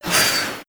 crit_throw.wav